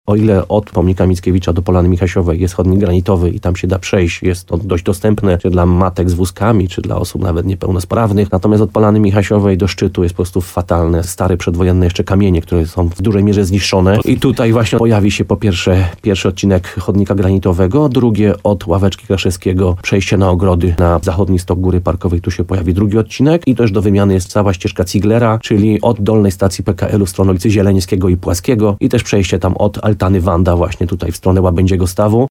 Jak mówił w programie Słowo za Słowo w RDN Nowy Sącz burmistrz uzdrowiska Piotr Ryba, to będą bardzo widoczne zmiany.